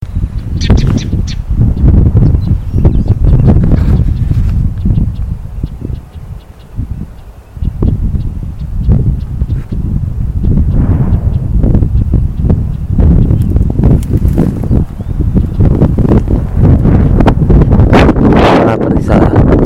Cistothorus platensis platensis
Nombre en español: Ratona Aperdizada
Localidad o área protegida: Reserva Natural Punta Rasa
Condición: Silvestre
Certeza: Fotografiada, Vocalización Grabada